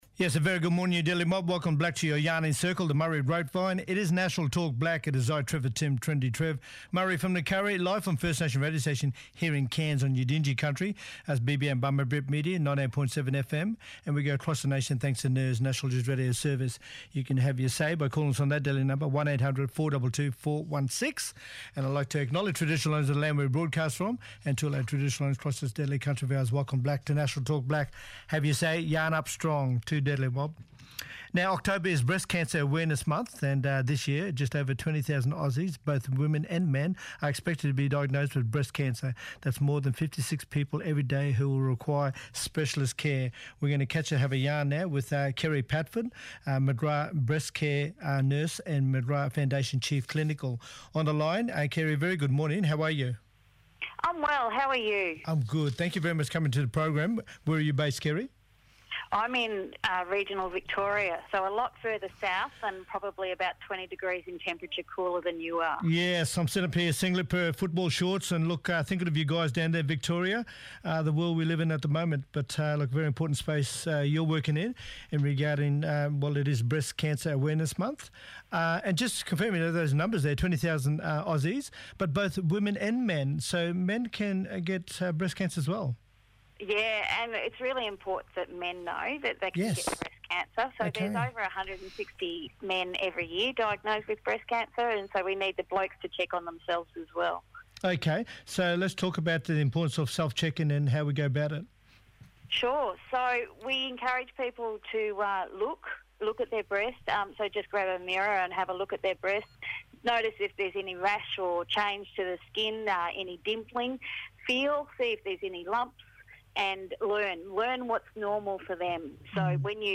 Delia Rickard, ACCC Deputy Chair, talking about how phone scams are ‘exploding’ and costing vulnerable Australians millions, new data shows.